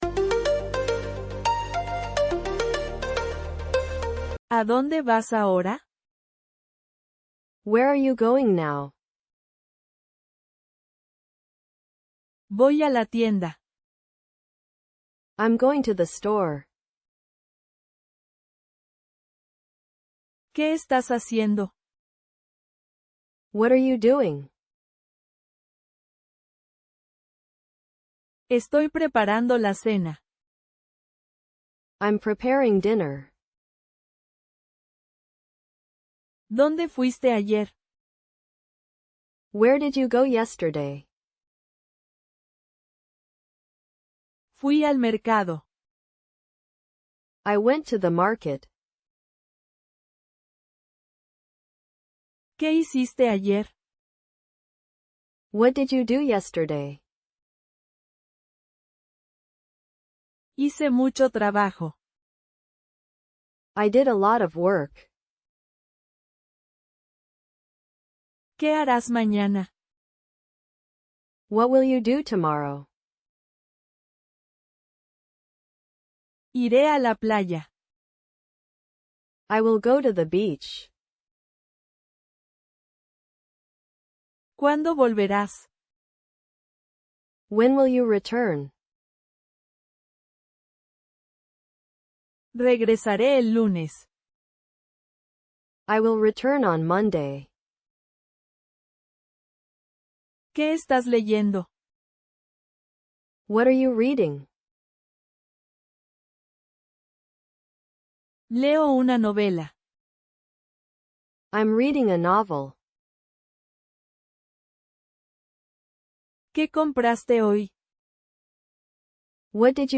200 Real Spanish Conversations for Everyday Situations